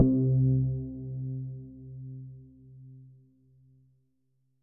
SPOOKY C2.wav